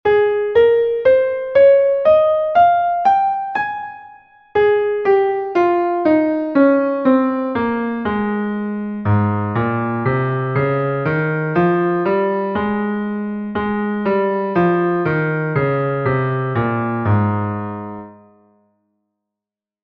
Gis-Dur, Tonleiter aufwärts und abwärts
Gis-Dur.mp3